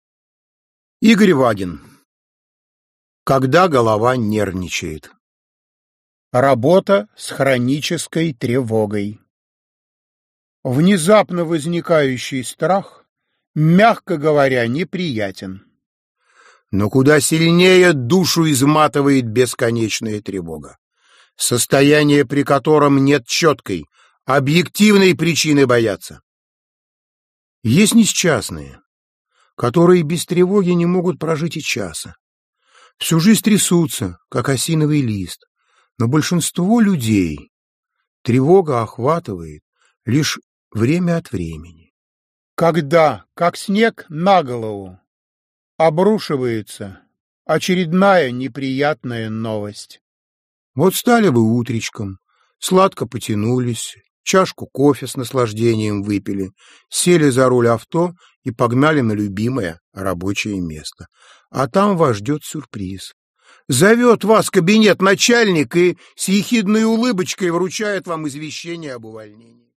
Аудиокнига Как перестать нервничать | Библиотека аудиокниг